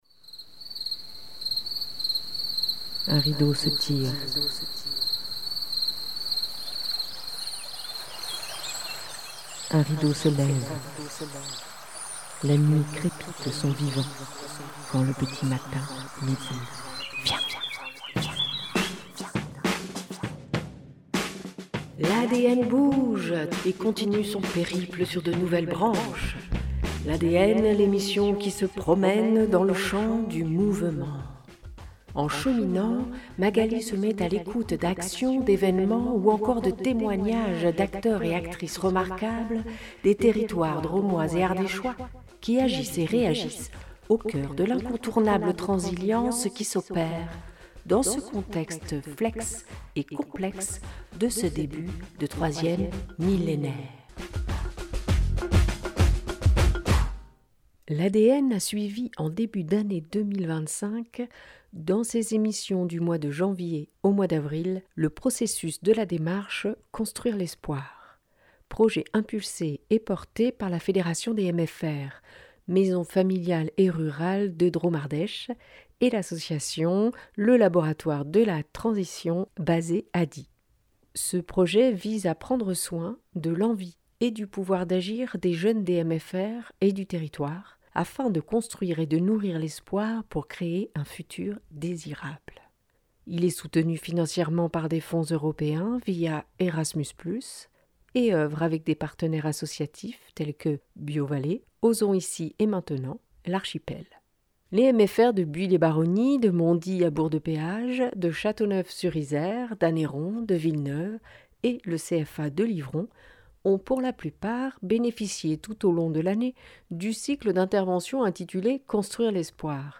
Dans cet épisode, vous entendrez les voix de trois jeunes co-organisateurices du Festival Jeunes#2, porté par la Fédération des MFR Drôme Ardèche, qui a eu lieu les 21 et 22 mai dernier…